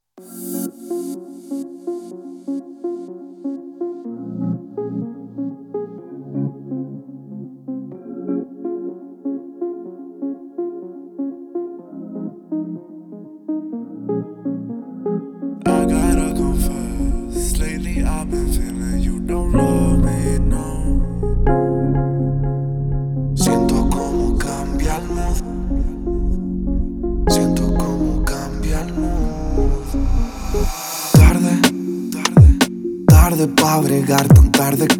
Жанр: Хип-Хоп / Рэп / Латино